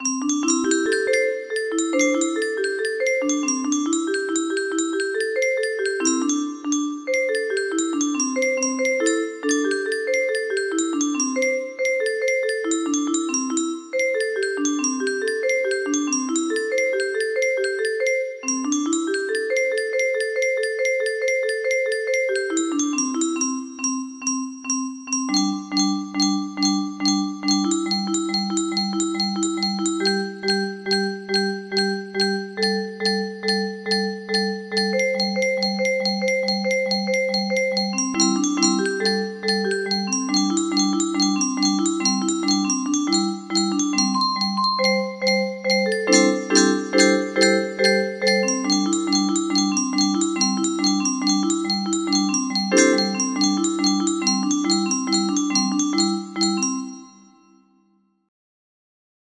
Y music box melody